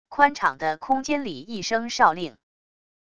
宽敞的空间里一声哨令wav音频